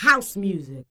07 RSS-VOX.wav